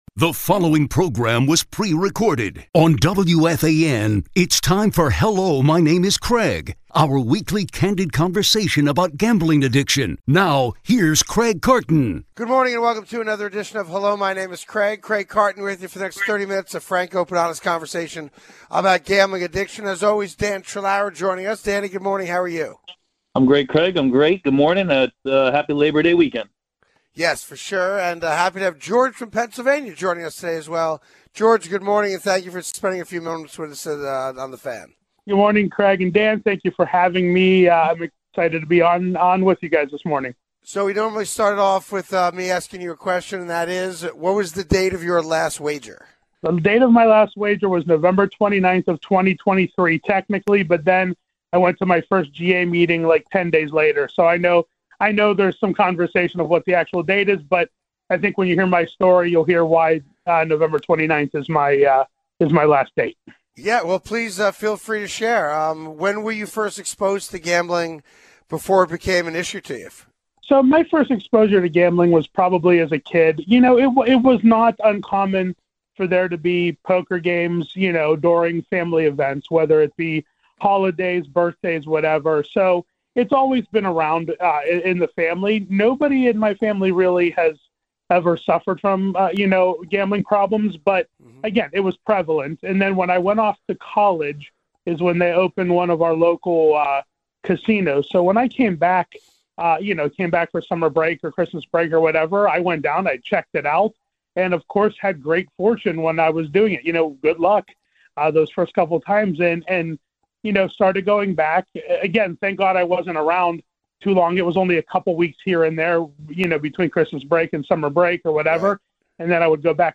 A WEEKLY CANDID COVERSATION ON GAMBLING ADDICTION.